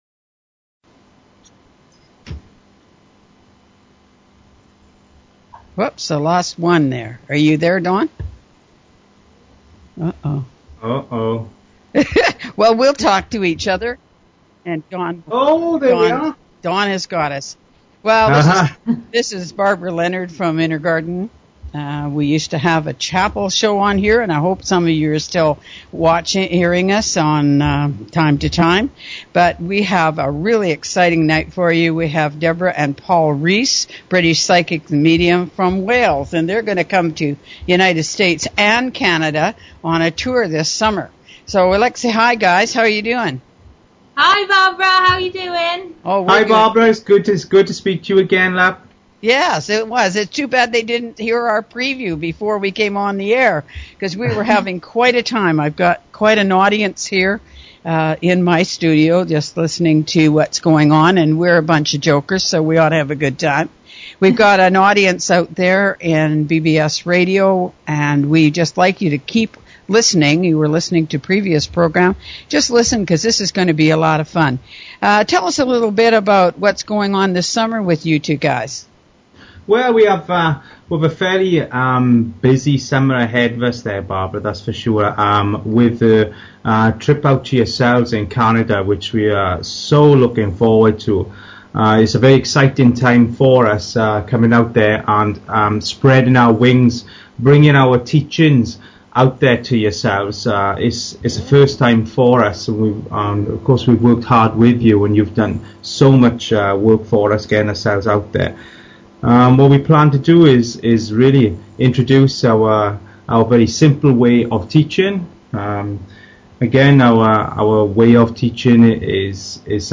Talk Show Episode, Audio Podcast, One_of_a_Kind_Psychic_Medium and Courtesy of BBS Radio on , show guests , about , categorized as